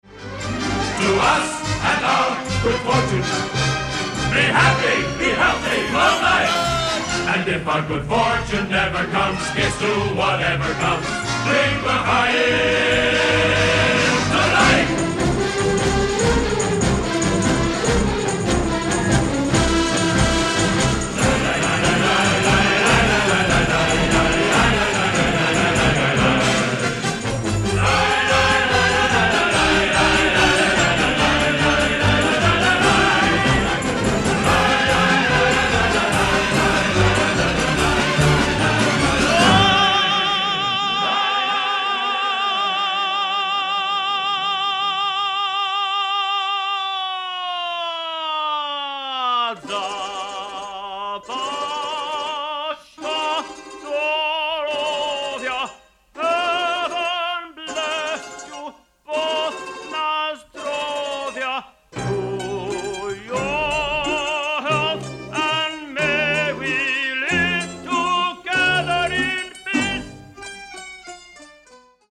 virtuoso violin soloist